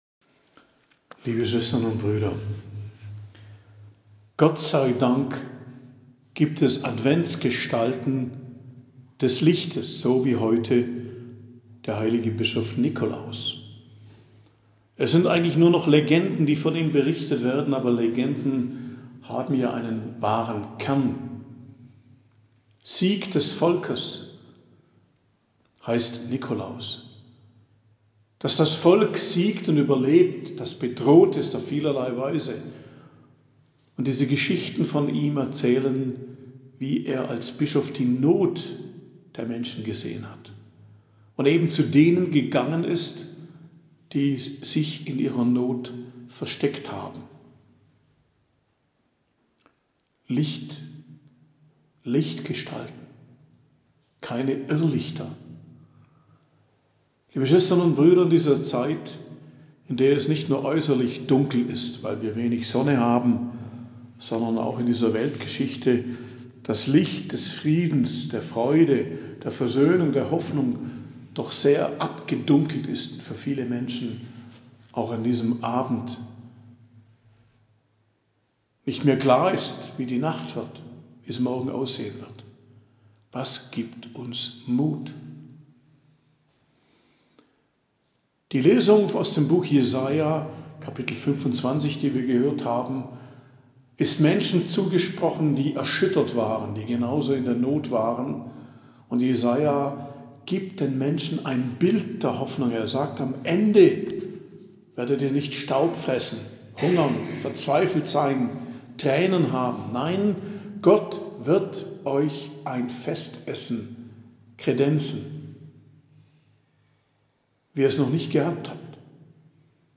Predigt am Mittwoch der 1. Woche im Advent, 6.12.2023